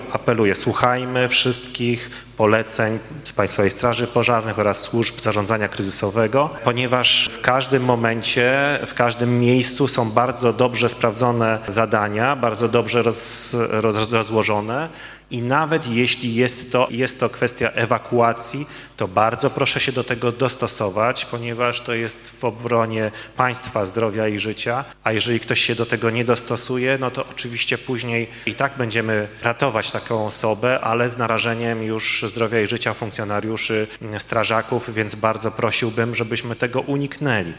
Wojewoda dolnośląski apeluje do mieszkańców Dolnego Śląska, by słuchać się poleceń wydawanych przez straż pożarną, policję, wojsko.